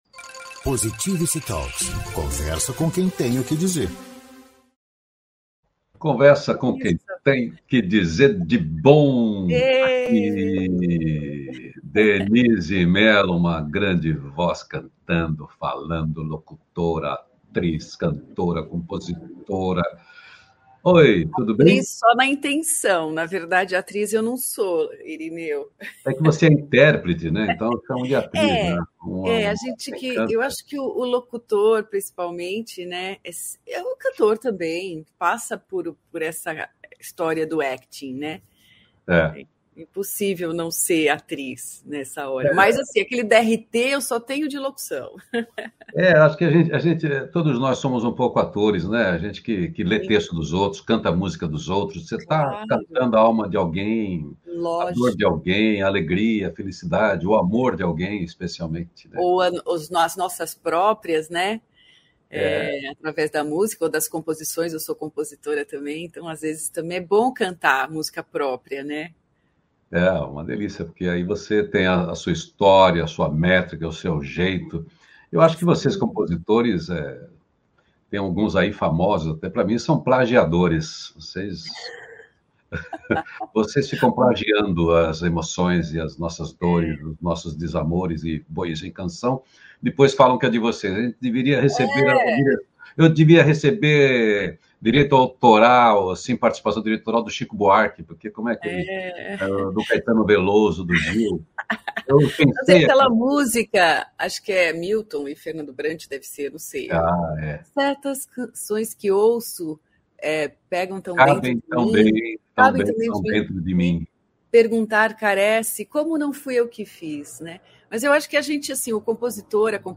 O programa é um contraponto leve ao noticiário hard predominante na mídia tradicional de rádio e tv. O Feliz Dia Novo, é uma revista descontraída e inspiradora na linguagem de rádio (agora com distribuição via agregadores de podcast), com envolvimento e interatividade da audiência via redes sociais.